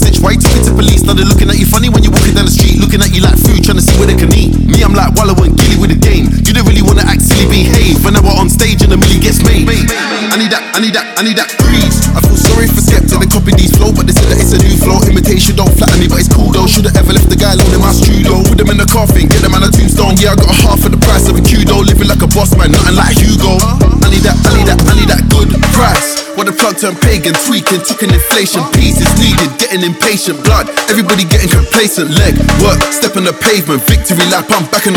Electronic Dance
Жанр: Танцевальные / Электроника